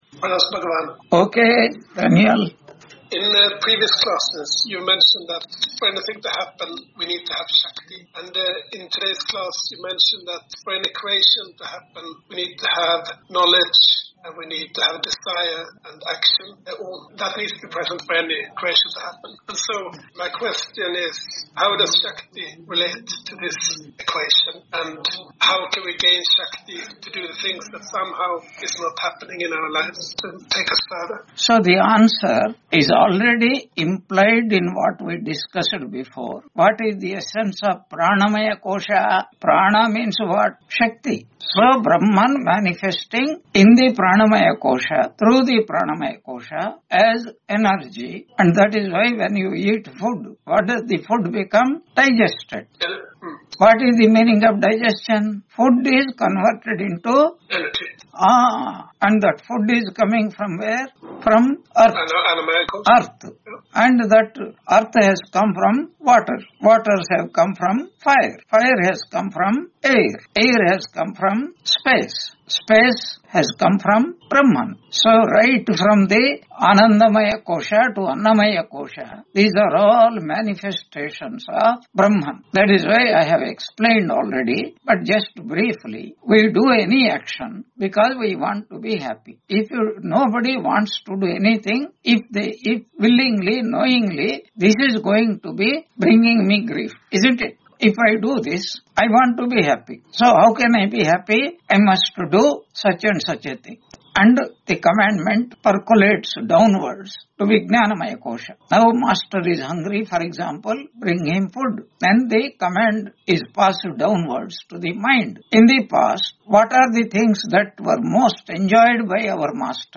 Taittiriya Upanishad Lecture 78 Ch2 6.1 on 12 November 2025 Q&A - Wiki Vedanta